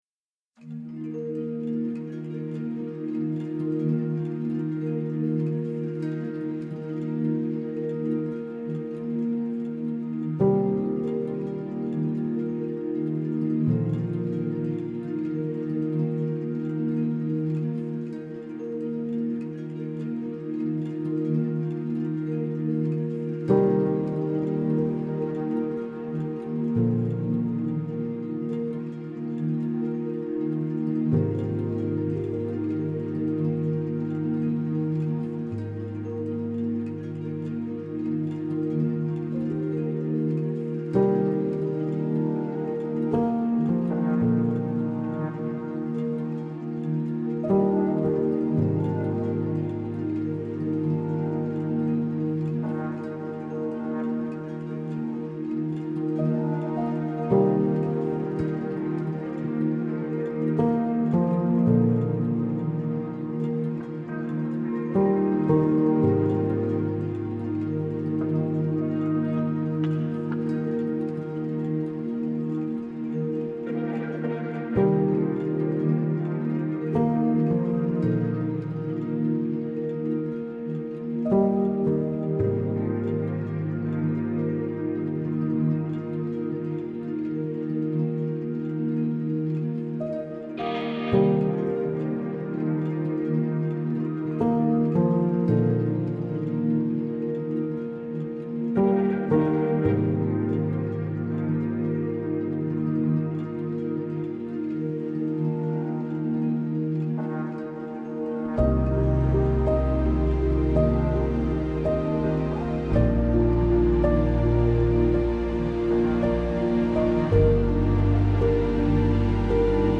آرامش بخش پیانو تخیلی و رویایی عصر جدید موسیقی بی کلام
موسیقی بی کلام نیو ایج